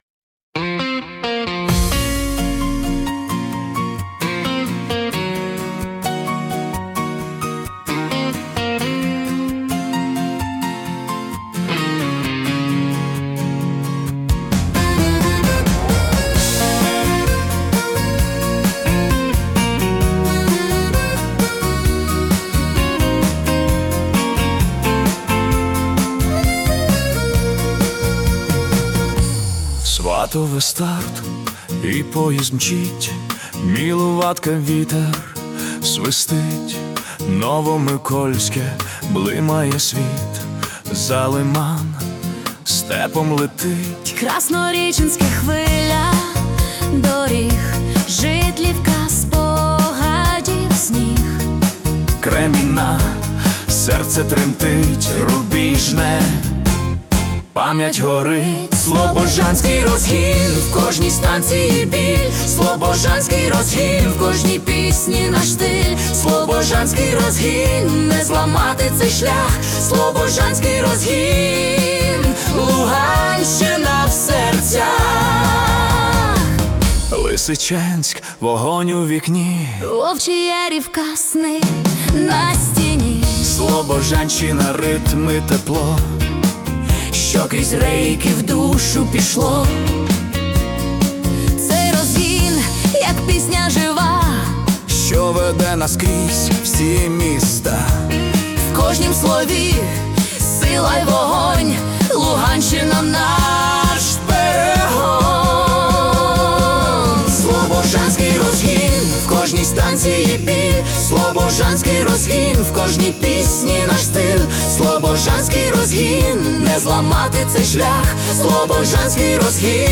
це драйвовий шансон-поп (130 BPM)